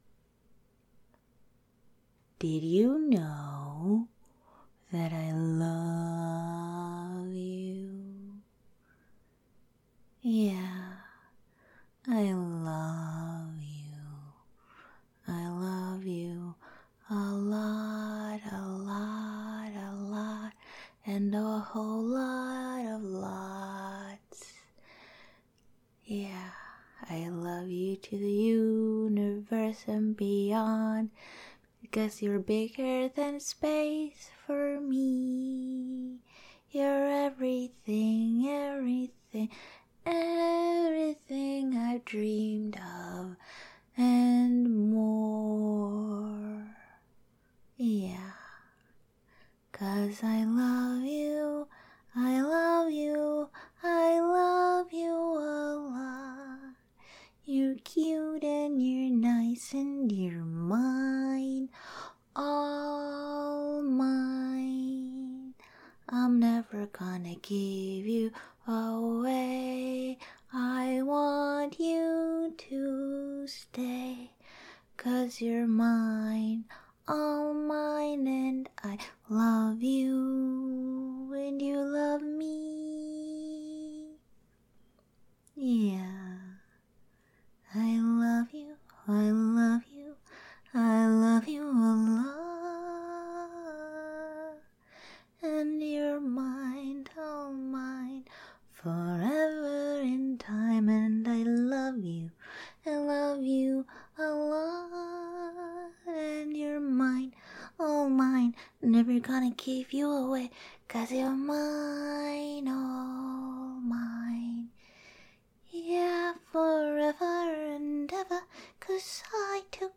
ASMR Girlfriend Roleplay